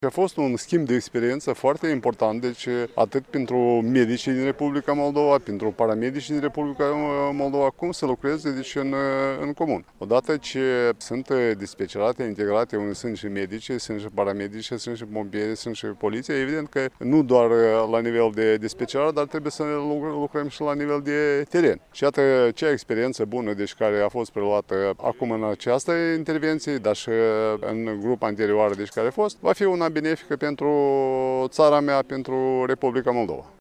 Astăzi, s-a desfăşurat, la Iaşi, ceremonia militară care a marcat sfârşitul misiunii cadrelor medicale din Republica Moldova  la Spitalul Modular de la Leţcani.
La rândul său, şeful Inspectoratului General pentru Situaţii de Urgenţă din Republica Moldova, generalul maior Mihail Harabagiu, a spus că dispeceratele integrate funcţionează de mai mult timp, dar, de această dată, au fost armonizate şi procedurile din teren.